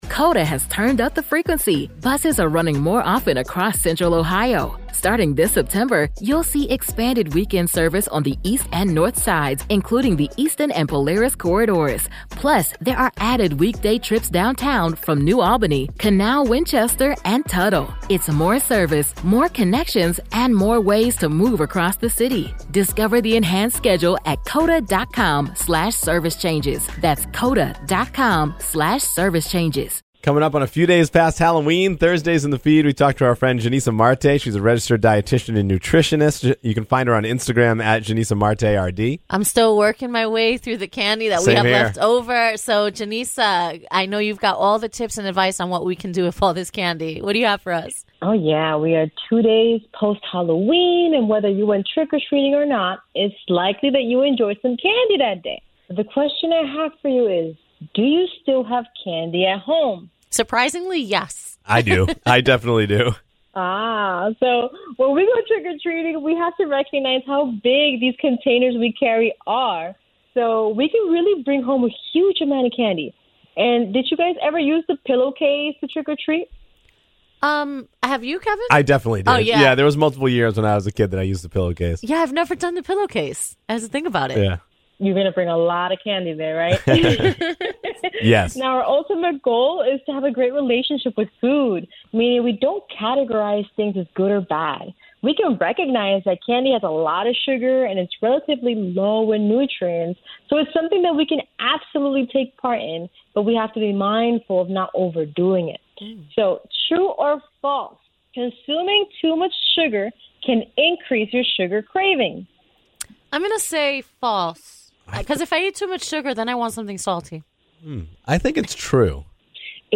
chat with an expert about all things health and wellness